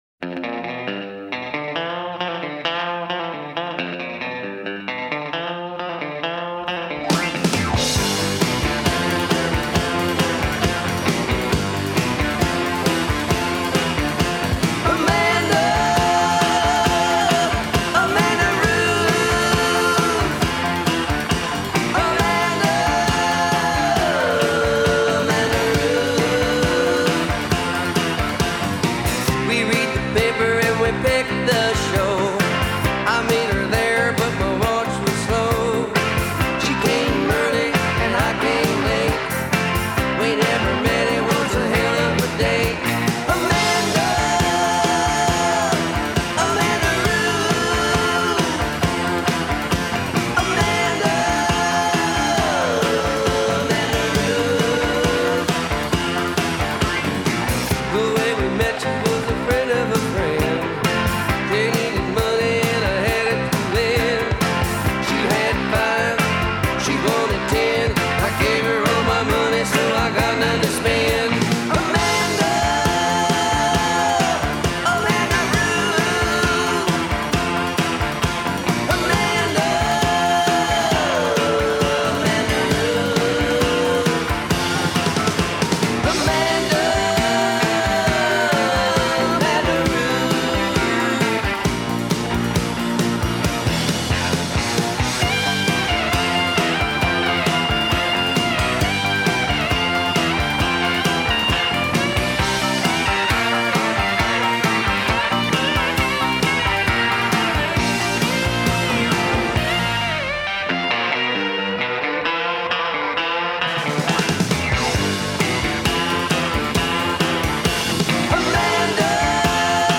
a great pop/country album